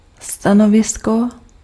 Ääntäminen
IPA : /pəˈzɪʃən/